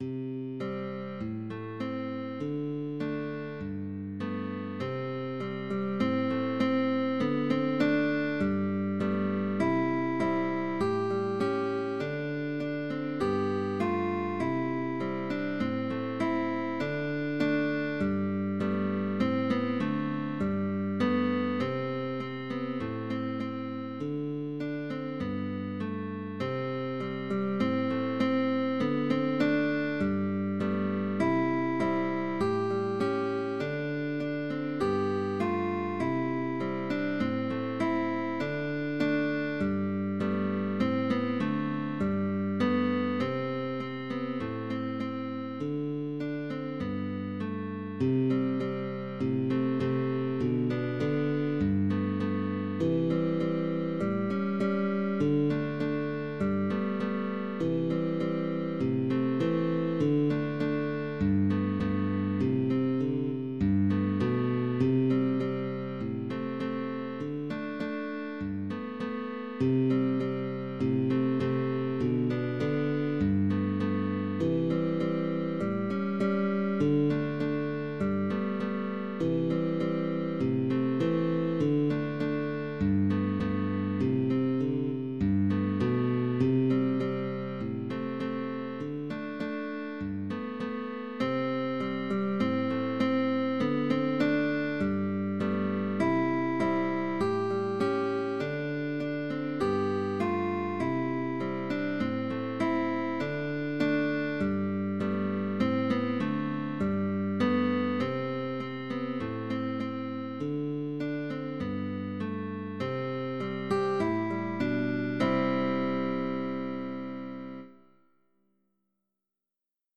Two popular songs from Latvia by guitar duo sheetmusic.
PUPIL AND TEACHER – GUITAR DUO
(pupil and teacher) Change of position, slurs, “apoyando”…